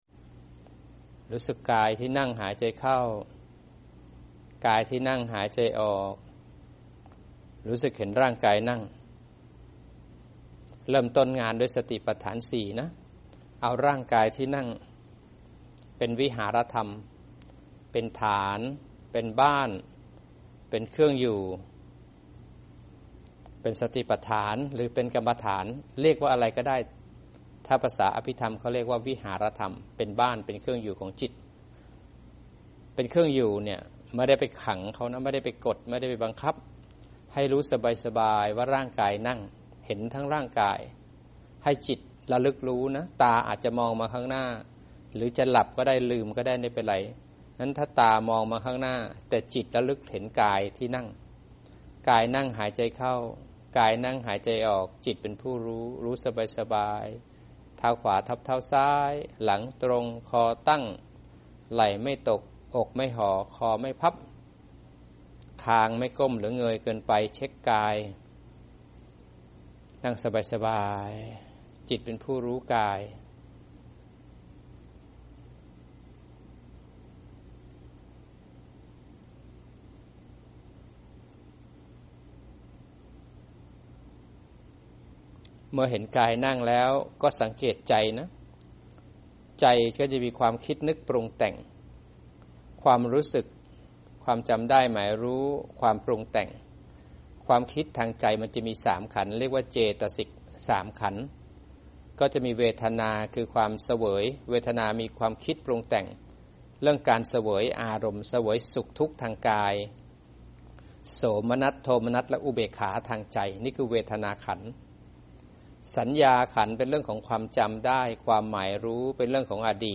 อจ.นำนั่งสมาธินำปัญญา